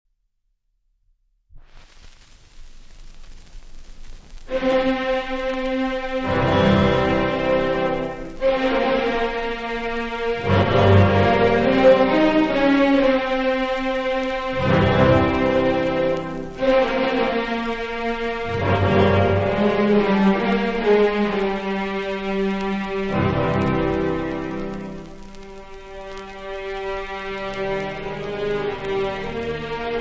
• orchestre
• rapsodie
• Rhapsody
• registrazione sonora di musica